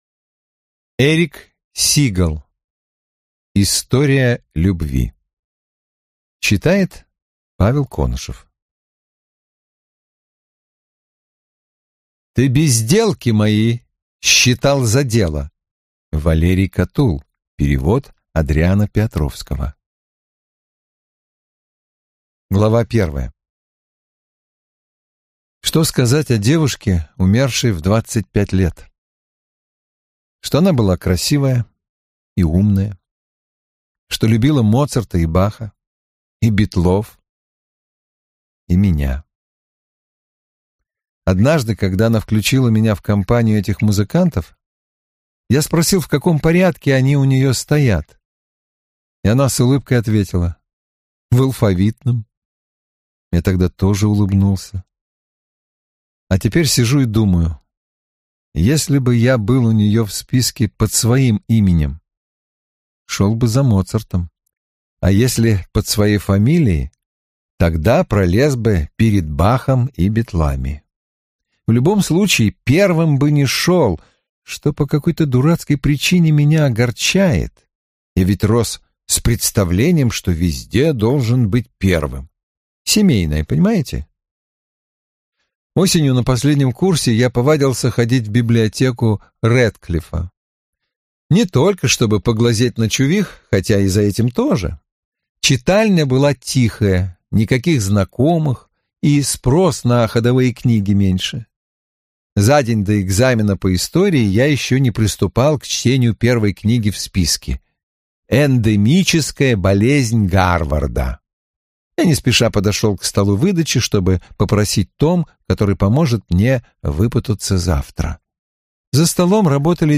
И ещё такой тёплый голос Владислава Галкина, и так отлично подобрана музыка...
Слушала аудиовариант в исполнении Владислава Галкина.
Великолепно читает!